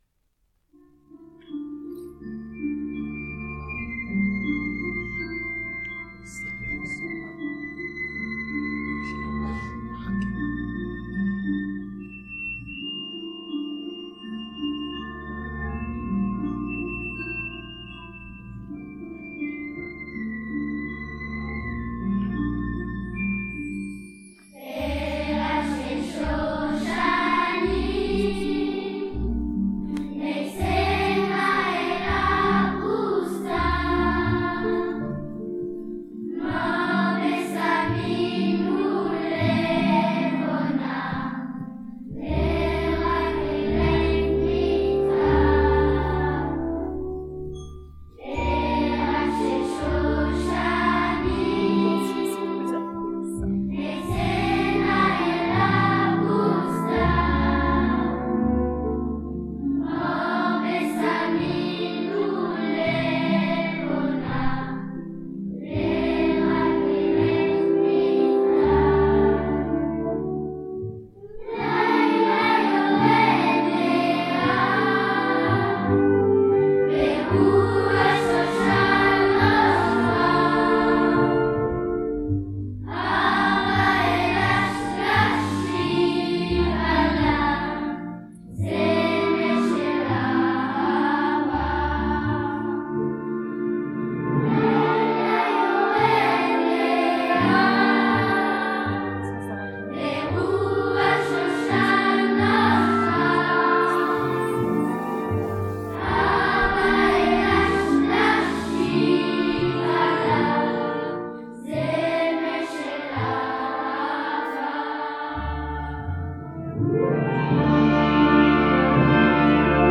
Et bien vous le saurez le 2 juin lors du Shabbat klezmer
piano
clarinette
Erev-shel-shoshanim-concert.mp3